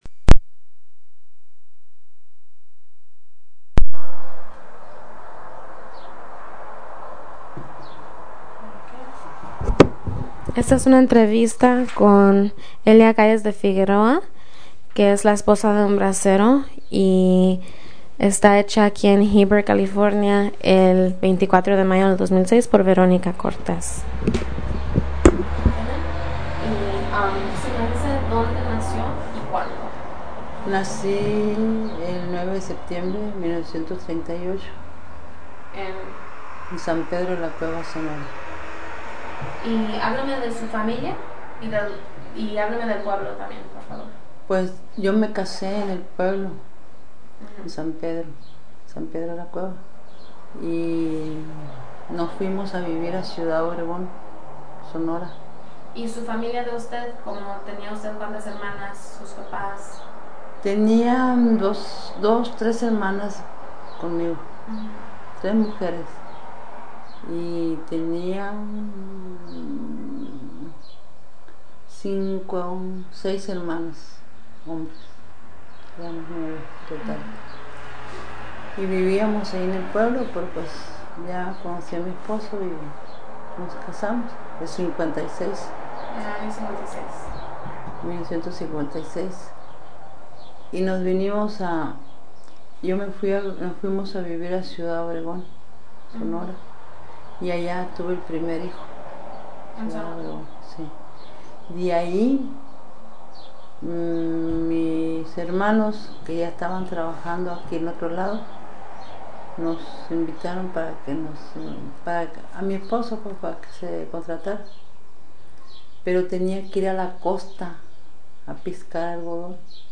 Location Heber, CA